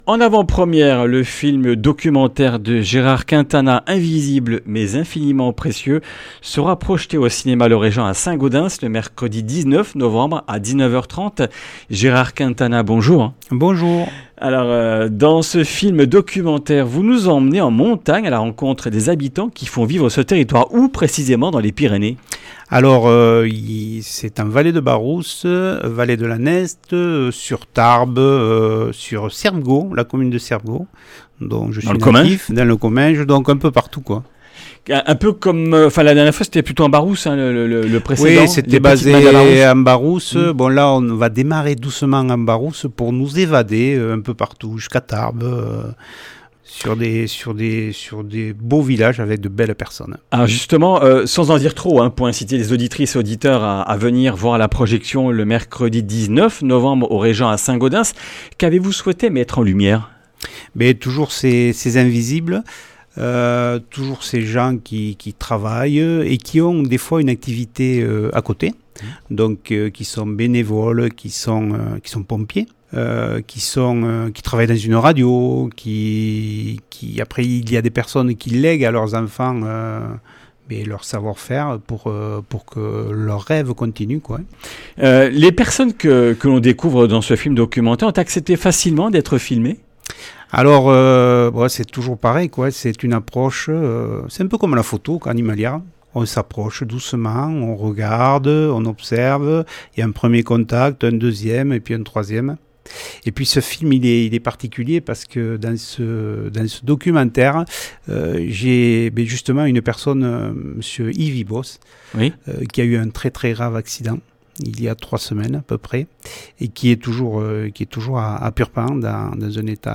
Comminges Interviews du 05 nov.